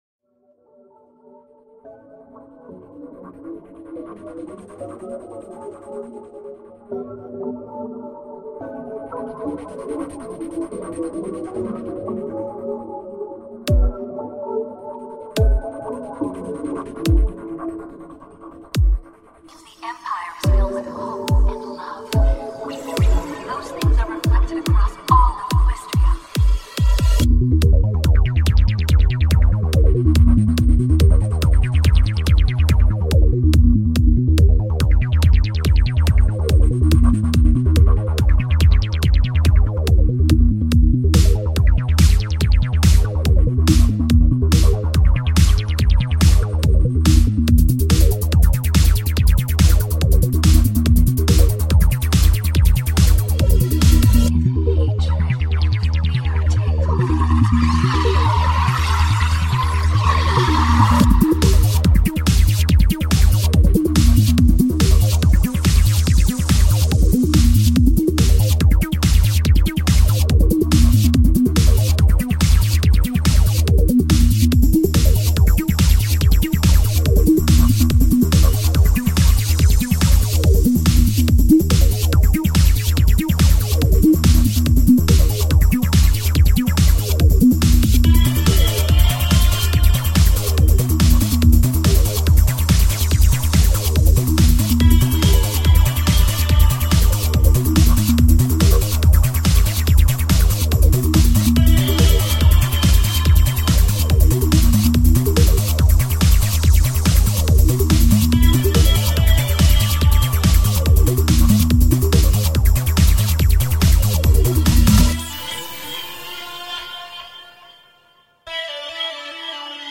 Have some old school trance right up in ya ear holes!